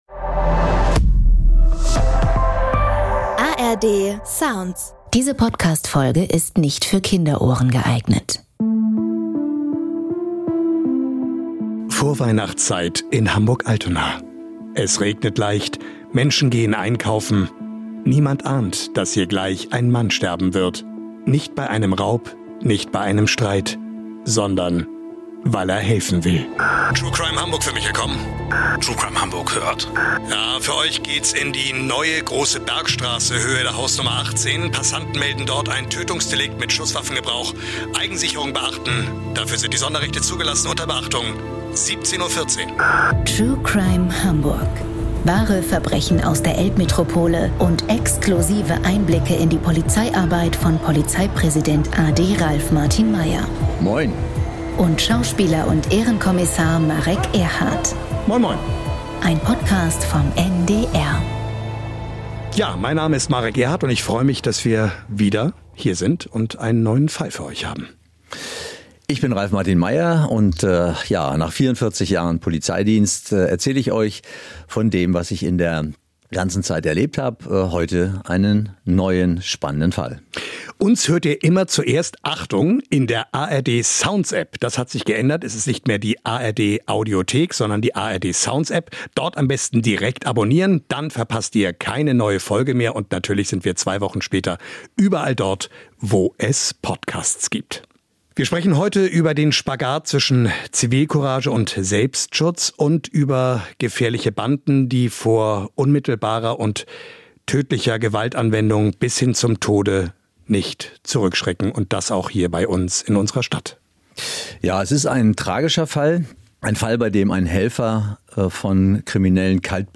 Im Gespräch mit Schauspieler Marek Erhardt schildert Hamburgs Polizeipräsident a.D. Ralf Martin Meyer, wie der Mord an einem couragierten Helfer Teil eines eskalierenden Bandenkriegs wurde und warum die Polizei ihre Strategie im Kampf gegen organisierte Kriminalität grundlegend verändern musste.